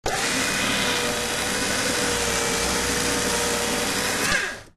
На этой странице собраны звуки работы шредера — от плавного жужжания до резкого измельчения бумаги.
Шредер альтернативный вариант